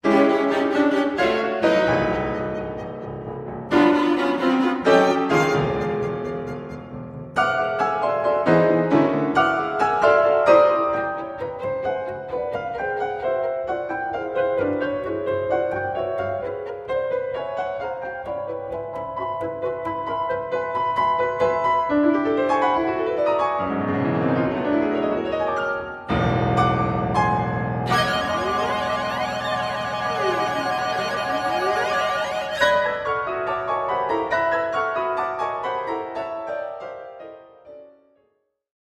Clarinet
Cello
Piano
Violin